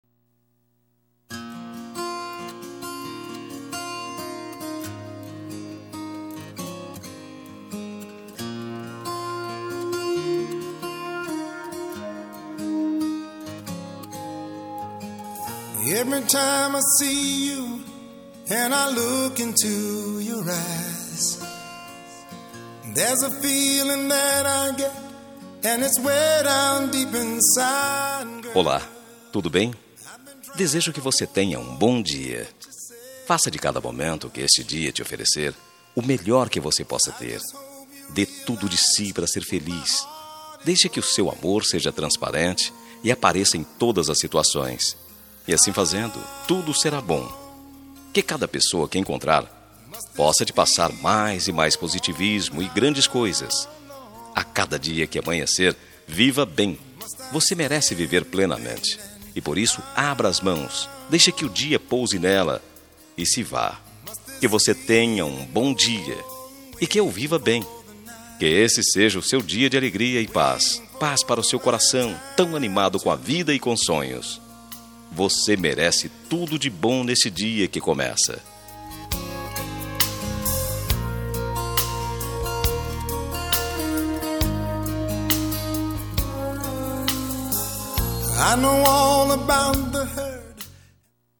Telemensagem de Bom Dia – Voz Masculina – Cód: 6329 – Geral
6329-dia-neutra-masc.mp3